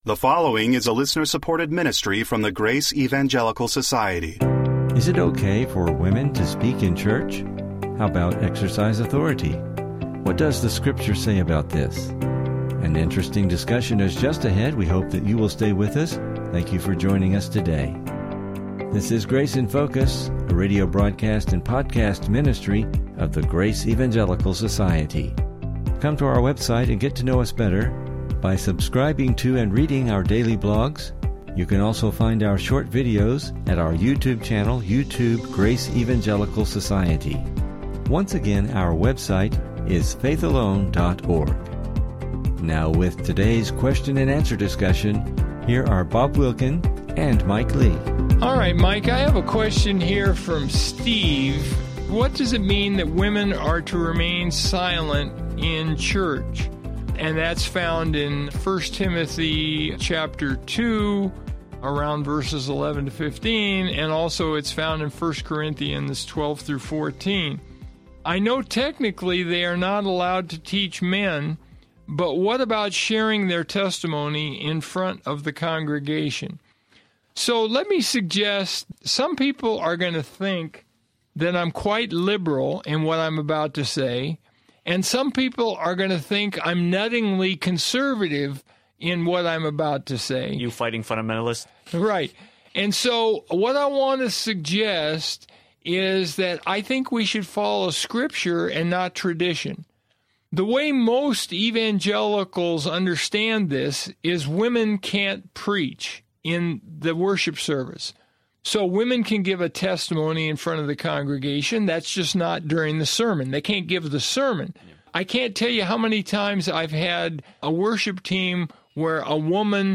What does Scripture say about this? What about women exercising authority in church? This will be an interesting discussion.